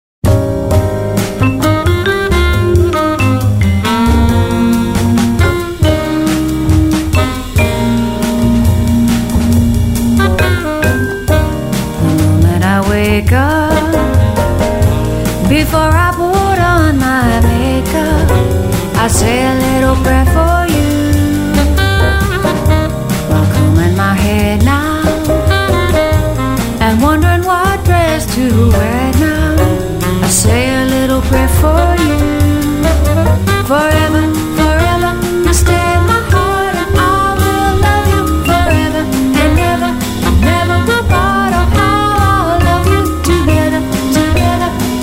vocal&bass
vibraphone
piano
Recorded at Avatar Studio in New York on March 23 & 24, 2011